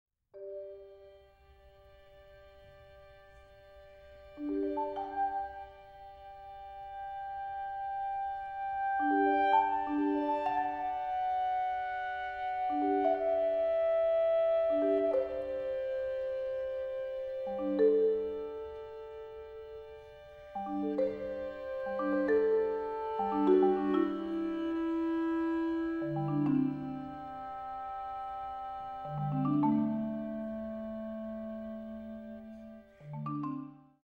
for marimba, violin and clarinet (2001)   16:20